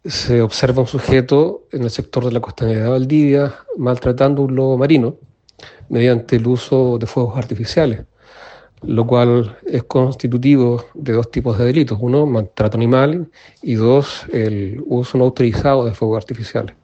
Ambos animales, al explotar y eyectarse el artefacto tipo petardo, reaccionaron asustados, mientras que el individuo se retiró del lugar, según quedó registrado en un video que circula por redes sociales, tal como narró el fiscal, Carlos Bahamondes.